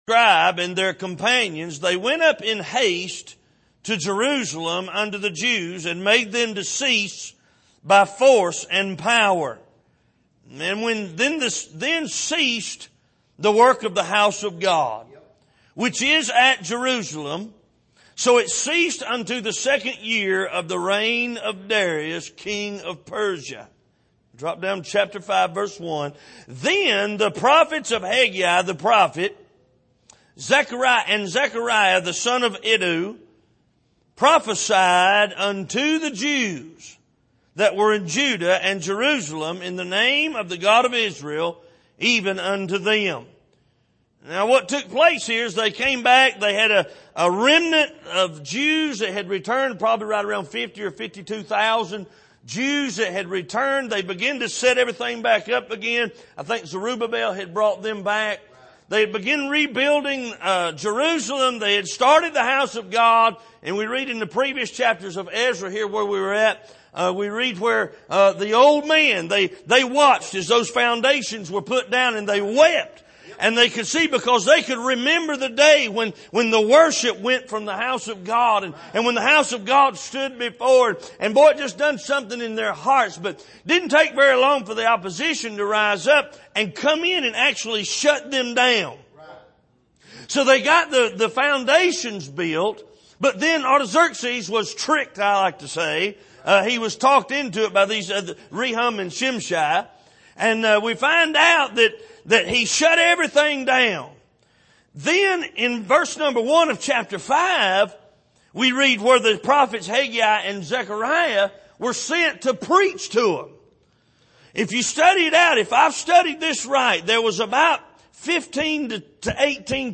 2021 Missions Conference Passage: Haggai 1:1-7 Service: Missions Conference A Bag With Holes « Being Identified With Christ In His Mission Why Give Him My Basket?